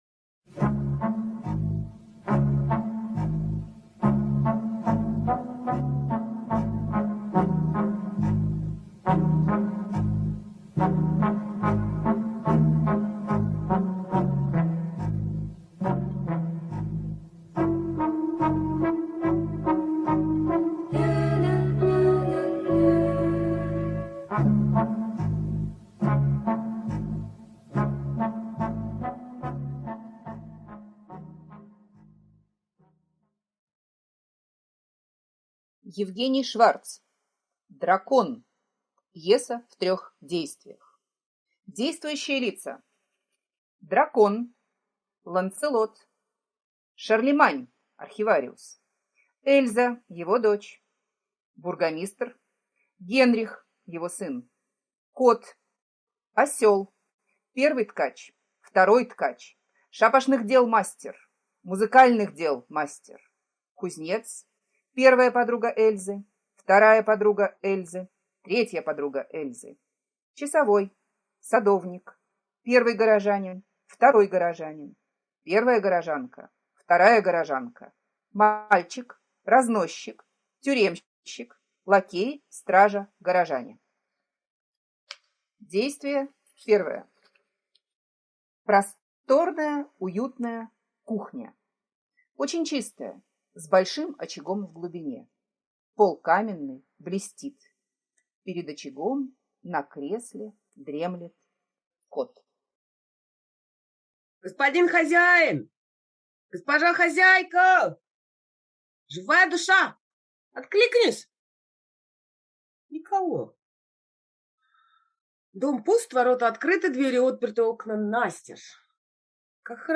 Дракон - аудио пьеса Шварца Е.Л. Пьеса-сказка в трёх действиях о том, что борьбу с драконом нужно начинать с себя.